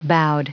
Prononciation du mot boughed en anglais (fichier audio)
Prononciation du mot : boughed